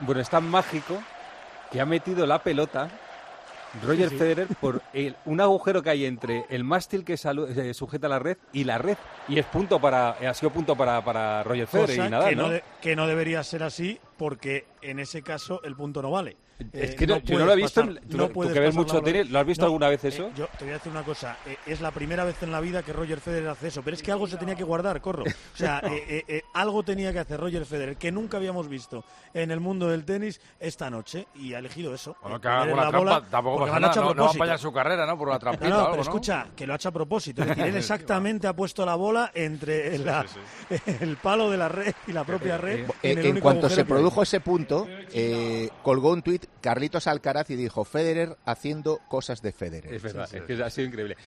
Federer nos regala un golpe mágico y surrealista en su despedida: Así lo contamos en El Partidazo de COPE